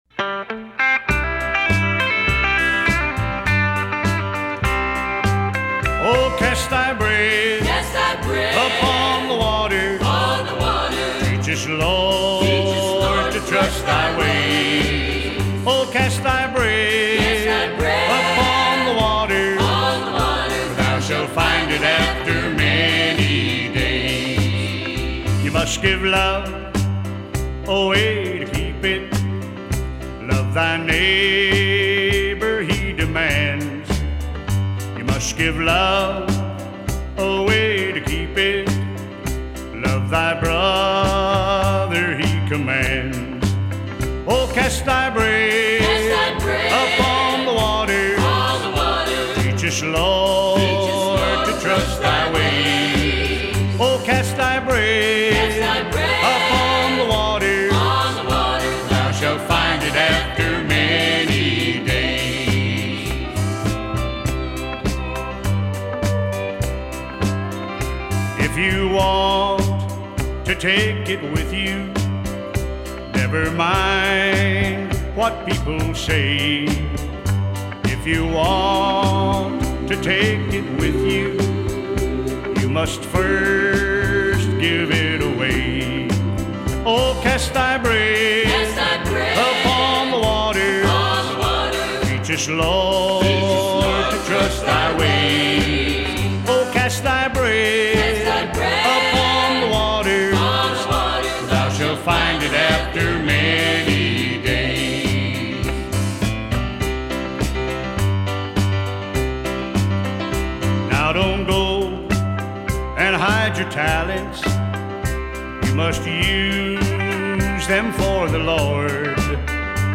Preacher: